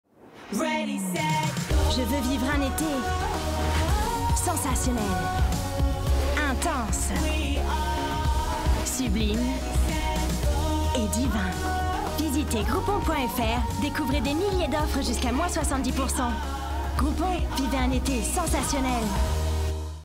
Native French Speaker Französische Sprecherin Französische Schauspielerin / French Actress
Sprechprobe: Werbung (Muttersprache):
Werbung Groupon FR - TV_0.mp3